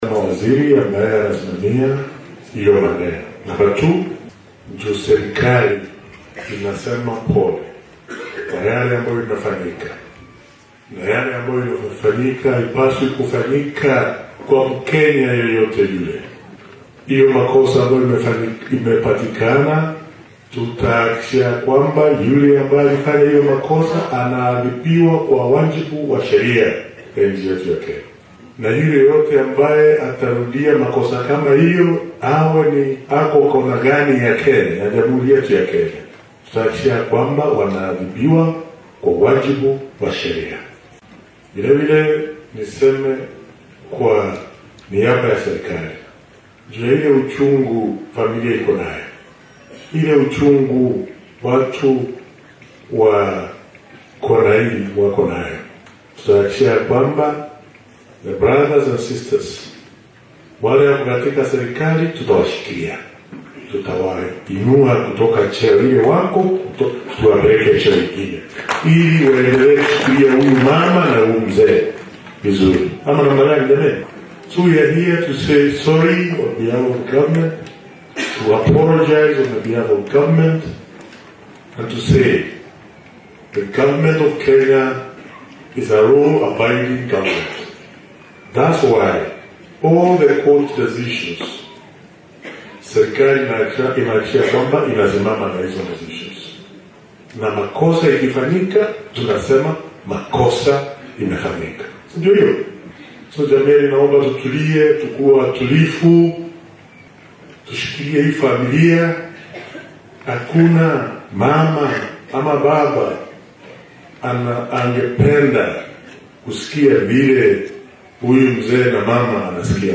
Wasiirka Wasaaradda Adeegga Dadweynaha, Horumarinta Raasumaalka Aadanaha iyo Barnaamijyada Gaarka ah,Ruku ayaa ka hadlay sida ay dowladda u garab istaagi doonto qoysaska dhibanayaasha.